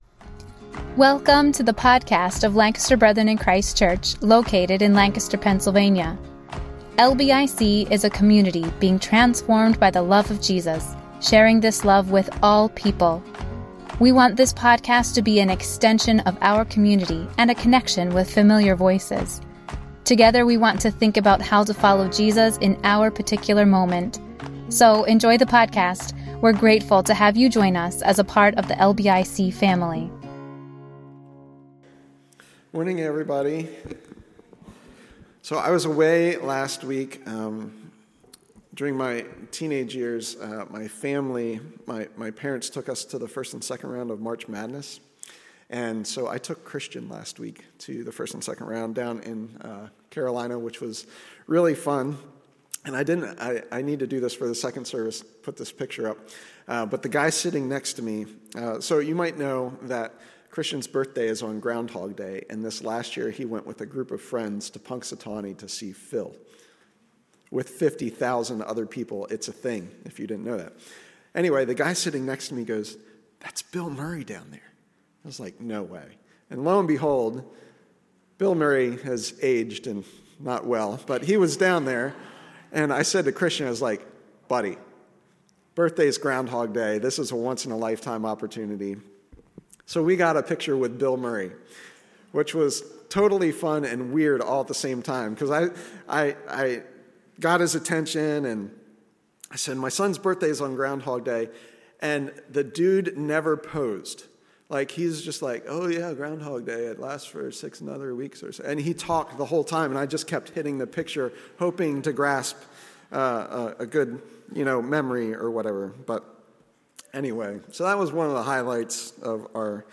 4th Sunday of Lent: Be Reconciled To God Service Message